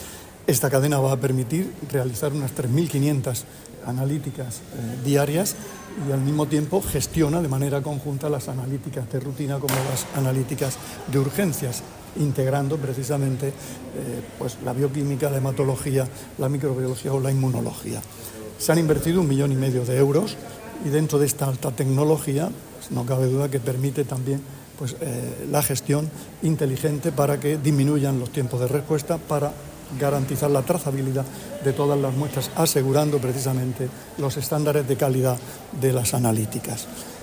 Sonido/ Declaraciones del consejero de Salud, Juan José Pedreño, durante su visita al laboratorio del hospital Rafael Méndez de Lorca.